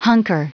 Prononciation du mot hunker en anglais (fichier audio)
Prononciation du mot : hunker